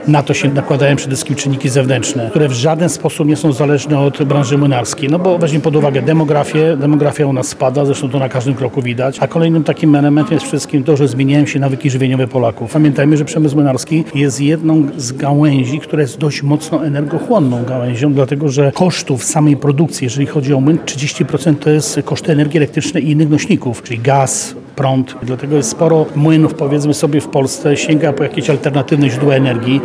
Sytuacja i perspektywy dla rynku zbóż oraz wyzwania dla przemysłu młynarskiego były jednymi z tematów poruszanych dziś (22.01) w Zamościu, podczas drugiego już Agro-Seminarium organizowanego przez Zamojskie Zakłady Zbożowe.